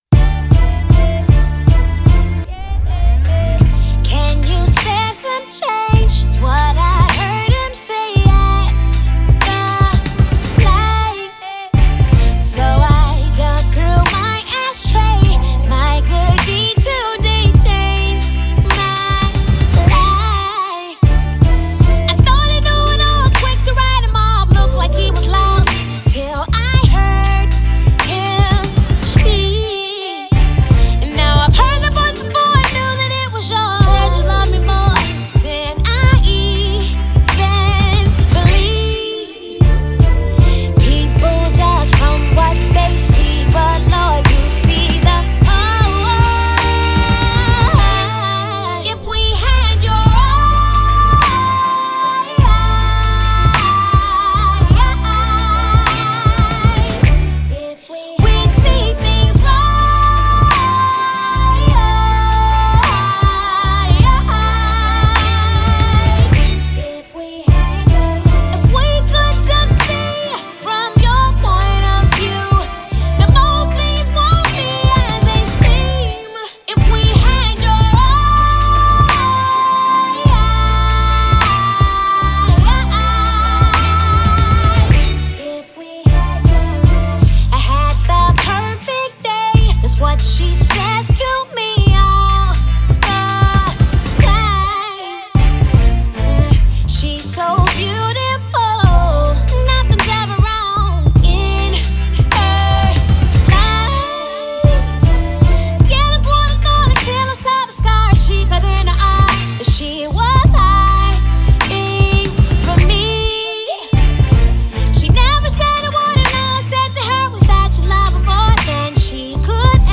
On the gospel track
let her vocals soar and leave everyone with goosebumps